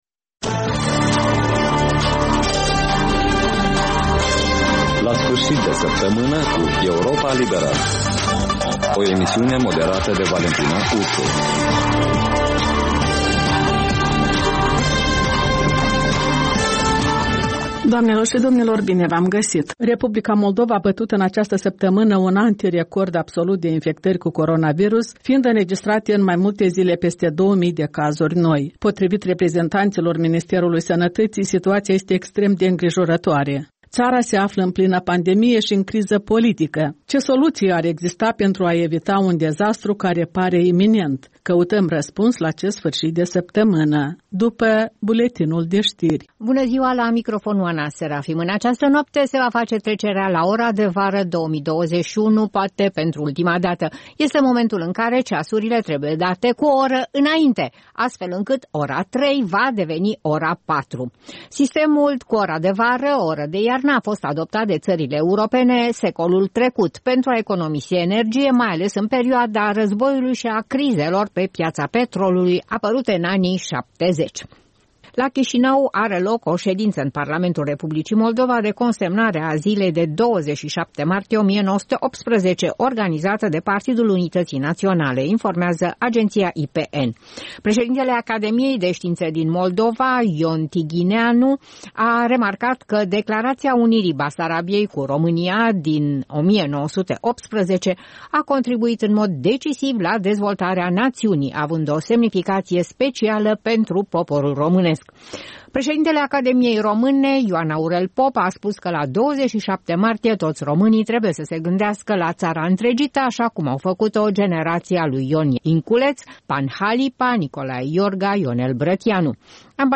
reportaje, interviuri, voci din ţară despre una din temele de actualitate ale săptămînii.